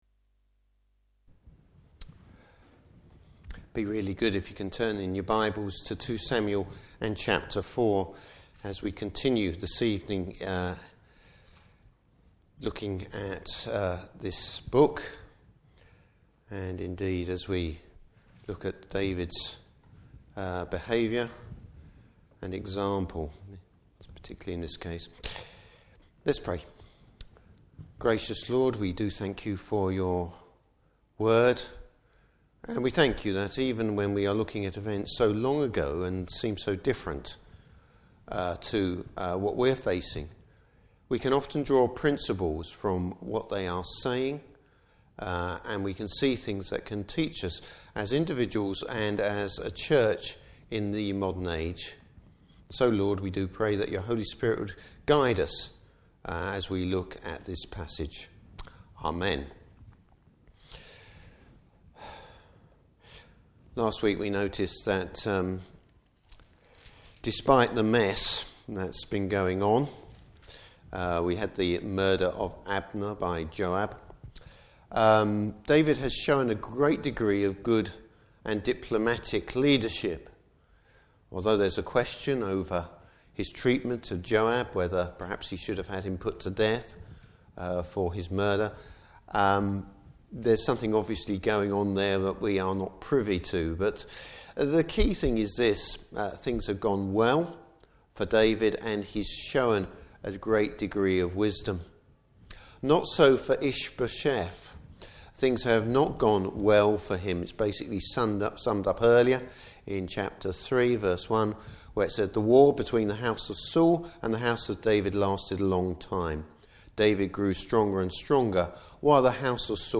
Service Type: Evening Service David shows consistency.